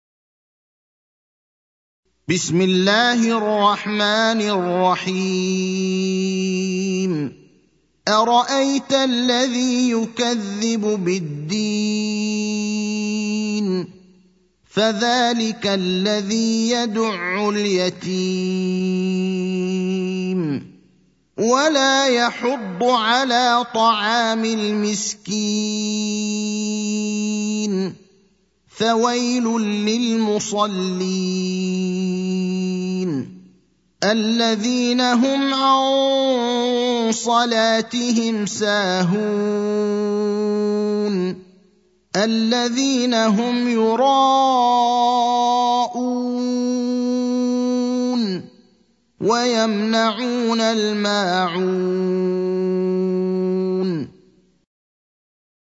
المكان: المسجد النبوي الشيخ: فضيلة الشيخ إبراهيم الأخضر فضيلة الشيخ إبراهيم الأخضر الماعون (107)